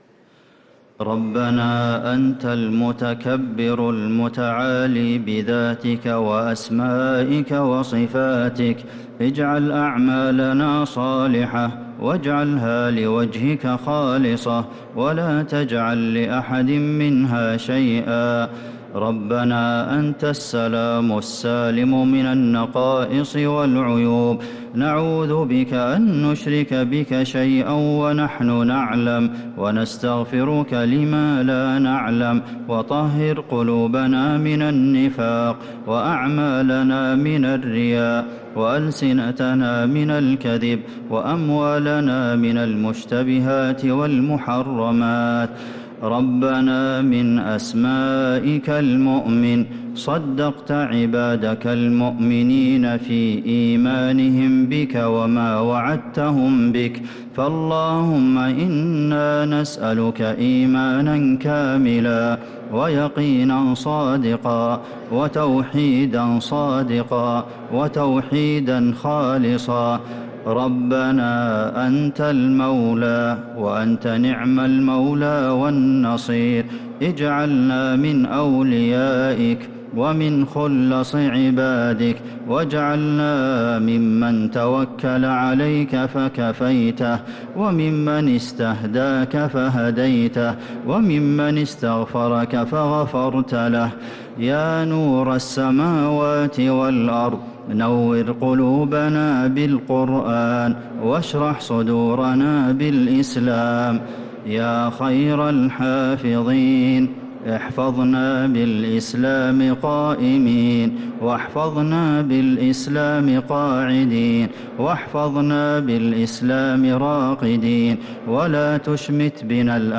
دعاء القنوت ليلة 20 رمضان 1446هـ | Dua 20th night Ramadan 1446H > تراويح الحرم النبوي عام 1446 🕌 > التراويح - تلاوات الحرمين